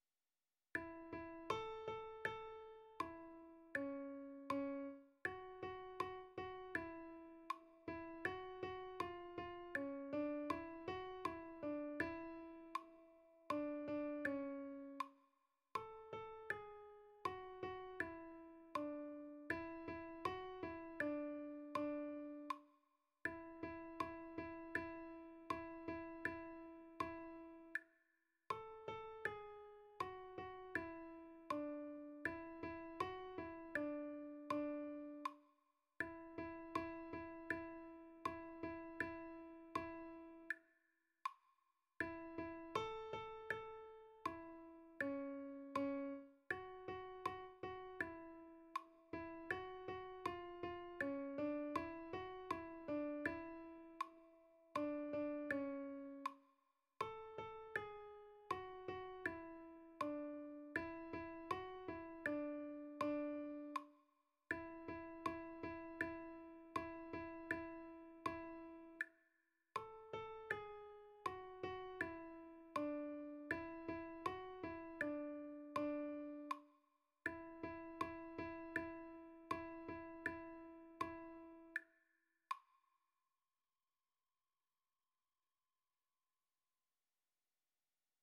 R�p�tition de la pi�ce musicale
Comme ton regard, mon autre_alto.mp3